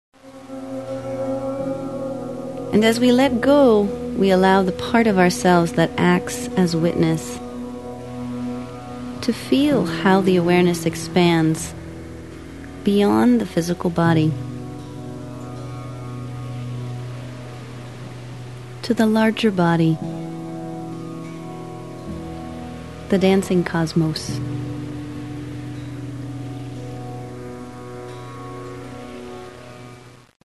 Instructional CD
The lovely music, a blend of soothing vocals, ocean sounds, Tibetan bowls and gongs, and traditional Indian instruments, on a separate CD, "Sea Sutras," in 2003.
YogaTherapy-FinishingPoses.mp3